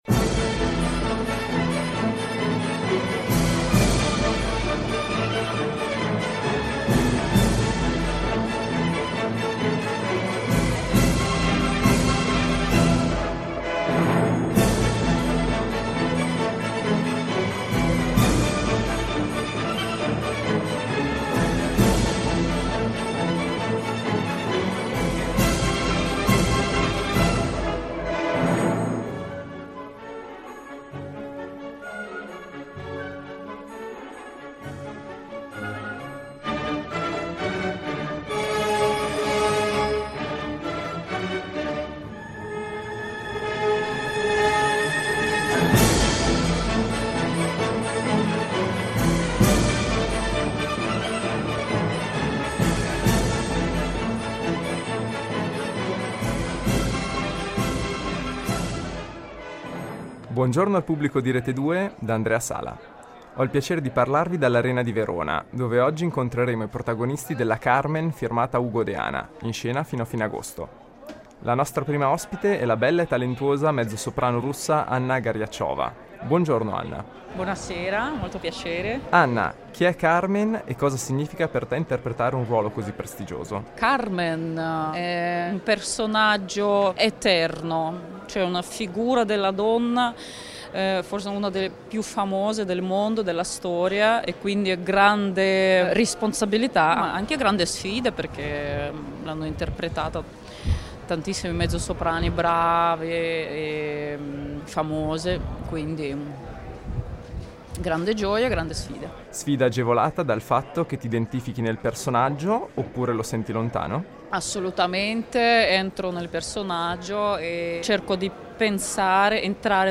Al suo microfono, in ordine di apparizione, potete ascoltare i solisti di fama internazionale Anna Goryachova (Carmen), Massimo Cavalletti (Escamillo), Serena Gamberoni (Micaela) e Francesco Meli (Don José).